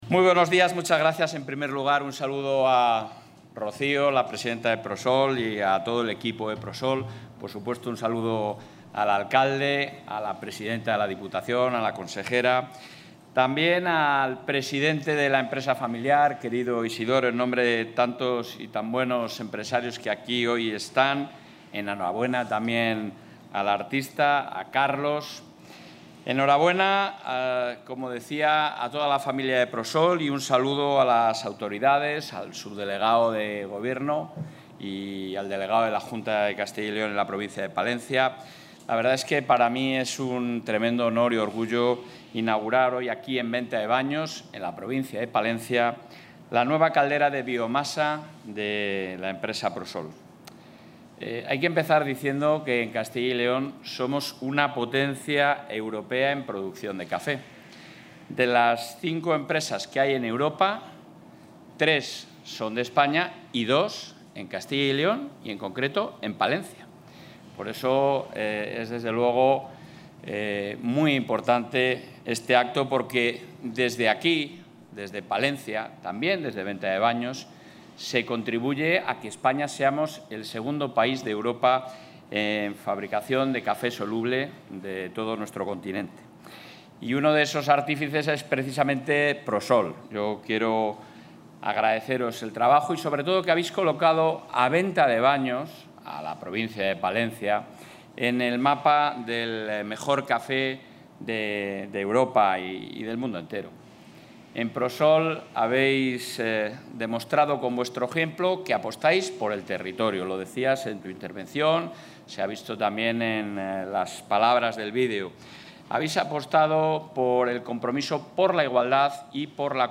Intervención del presidente de la Junta.
El presidente de la Junta de Castilla y León, Alfonso Fernández Mañueco, ha inaugurado hoy en Venta de Baños la nueva caldera de biomasa de la empresa PROSOL, subvencionada en parte por el Ejecutivo autonómico a través de una gestión eficaz de los fondos europeos, con el objetivo de incentivar la energía renovable térmica.